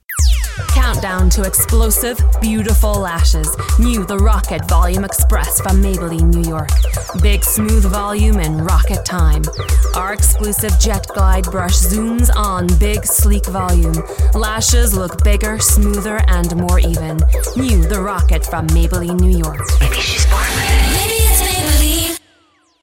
Commercial V/O Maybelline - Standard US Accent
Direct, Professional